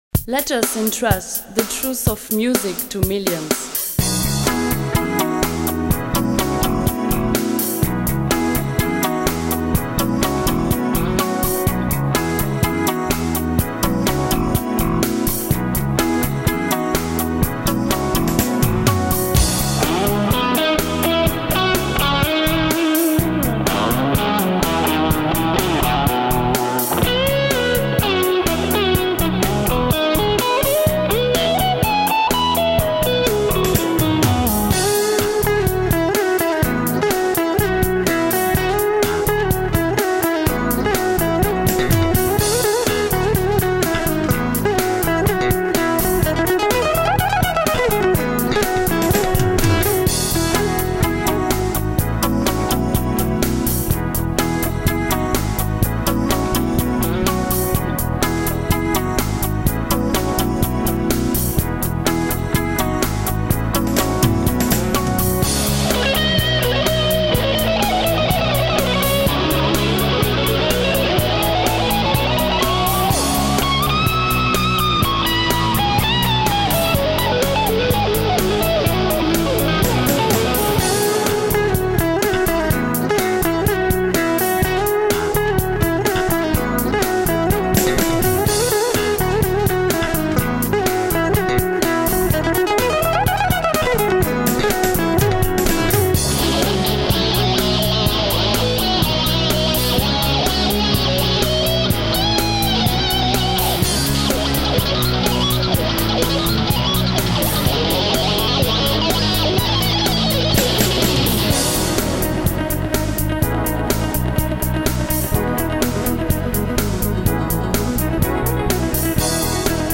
Mix Version